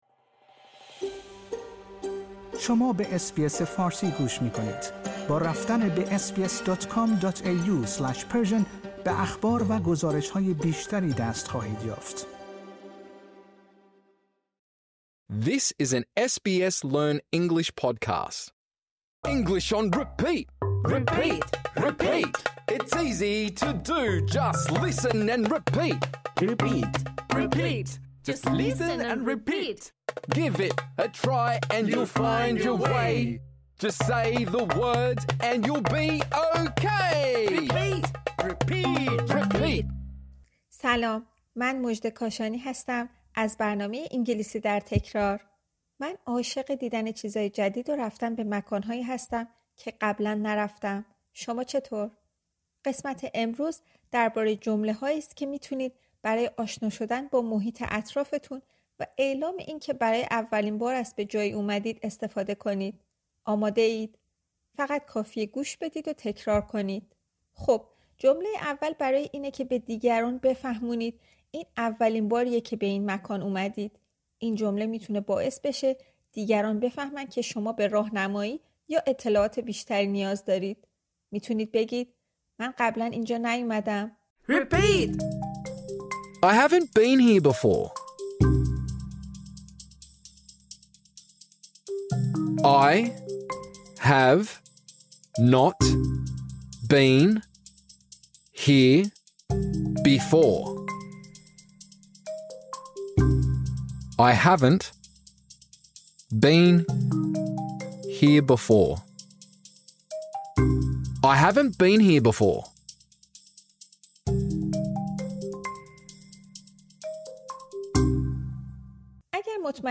این درس برای زبان‌آموزان در سطح آسان طراحی شده است.